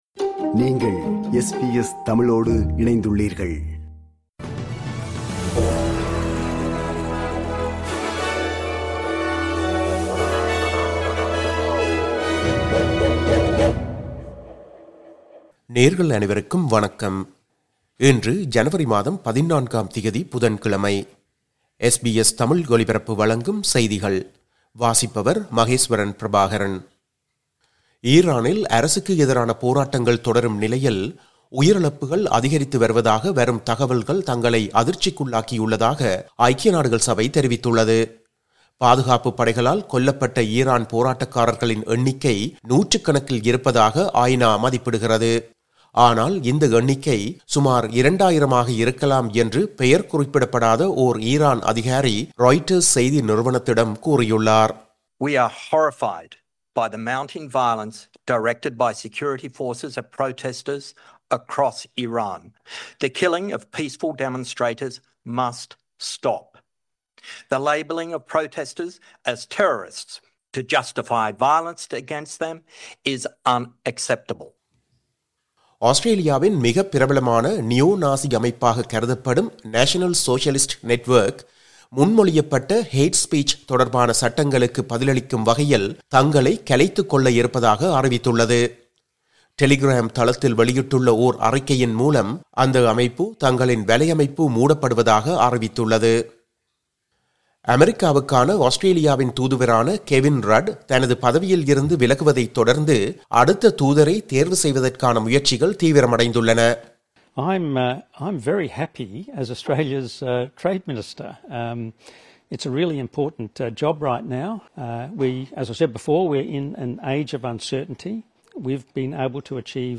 SBS தமிழ் ஒலிபரப்பின் இன்றைய (புதன்கிழமை 14/01/2026) செய்திகள்.